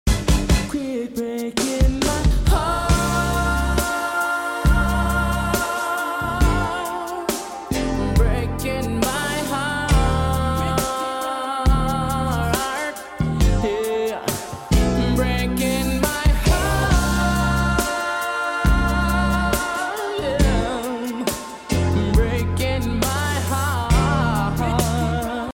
Boom Sound Effects Free Download
Mp3 Sound Effect boom